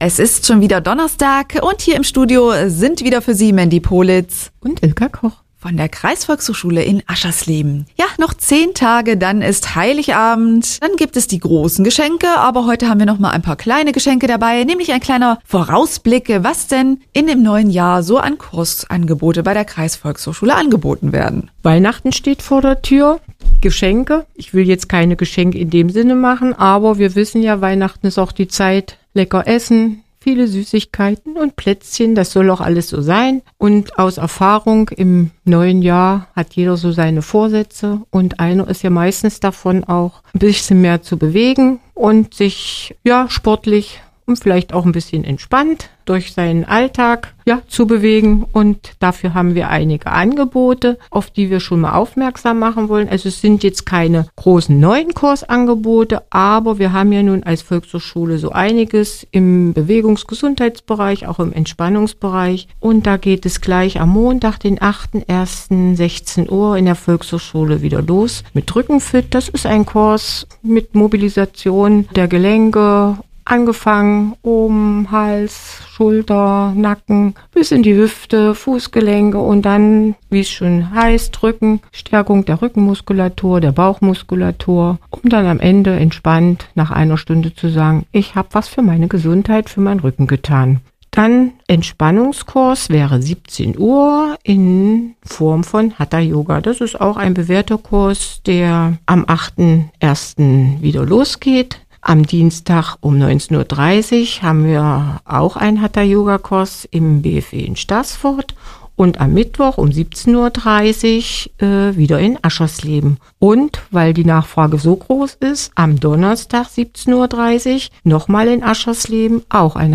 Hörbeitrag vom 14. Dezember 2023